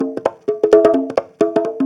Conga Loop 128 BPM (18).wav